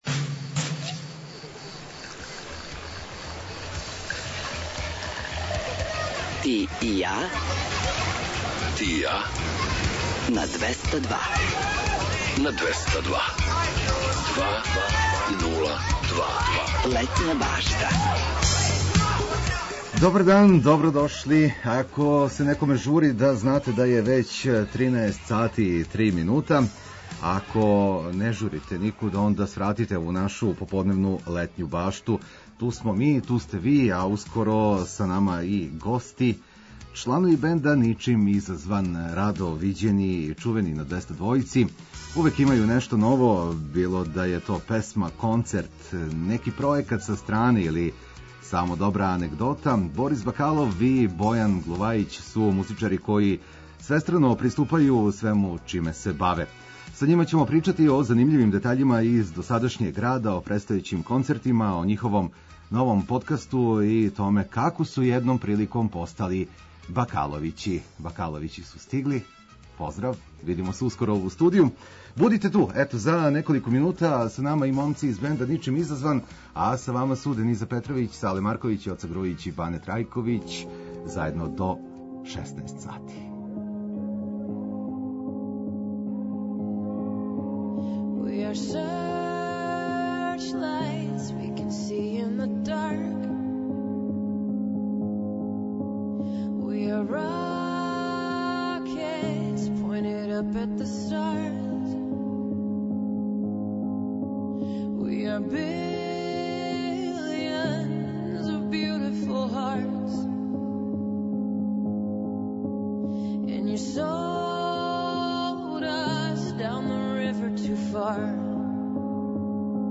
У госте нам долазе чланови бенда Ничим изазван!